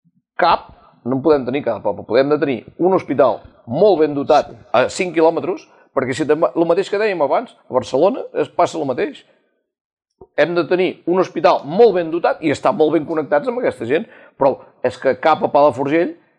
Debat Electoral Palafrugell 2019